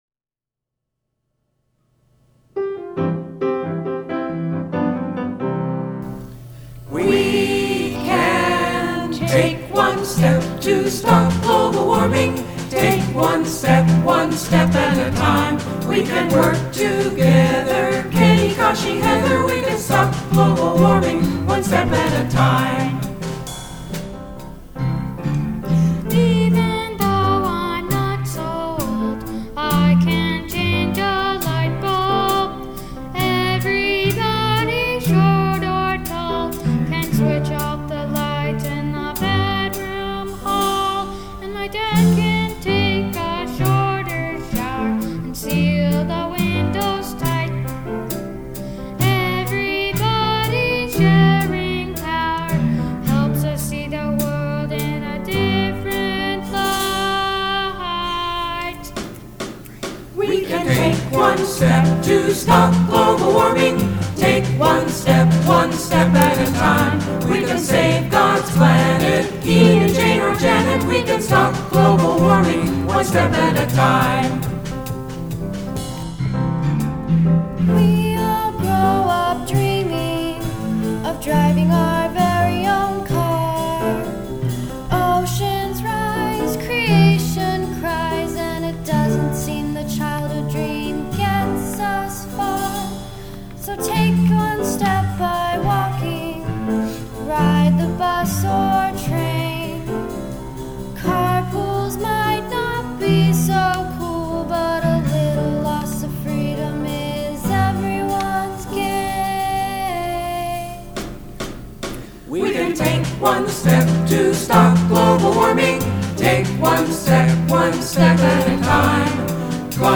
Another great message told in a lively, energetic, passionate way. Thank you to your great group of singers and musicians.